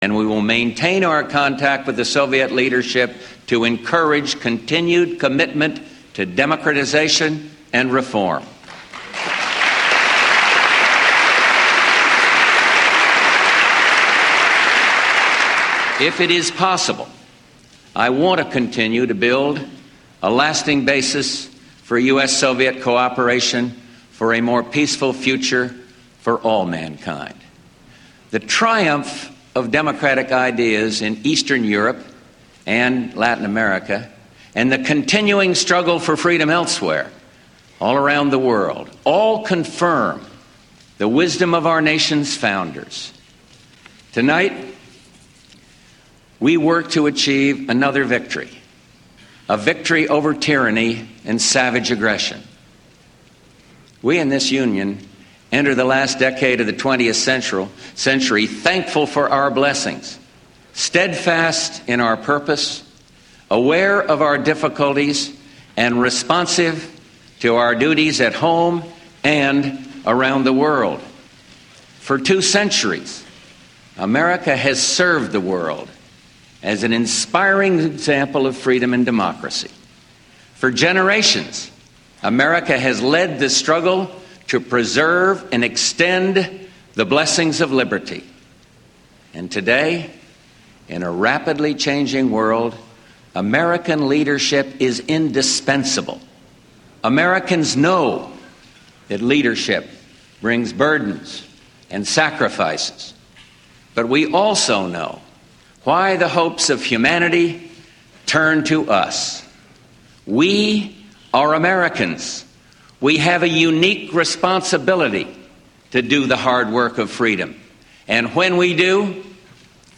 Tags: George H. Bush State of the Union George H. Bush George H. Bush speech State of the Union President